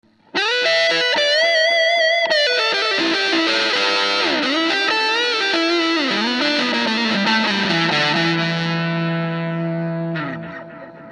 Аналоговый дилэй Electro-Harmonix Deluxe Memory Man
Fuzz Echo (130 кБ)
fuzz_echo.mp3